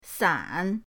san3.mp3